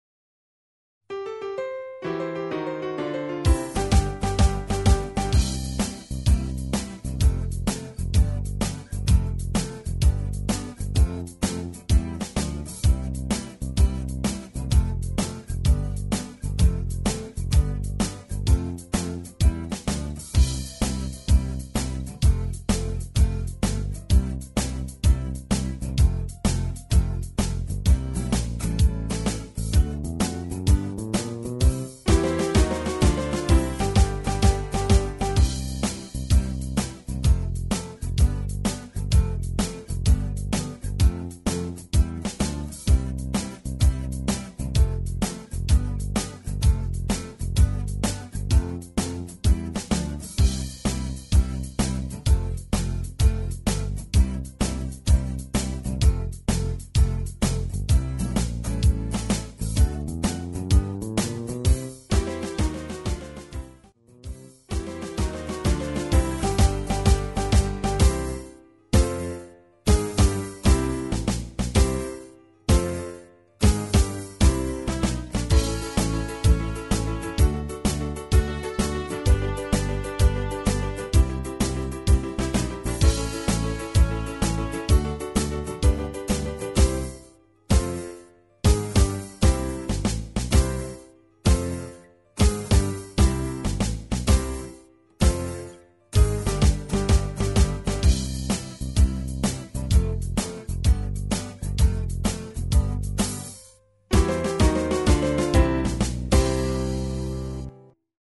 Instrumental TRacks
Rhythm Track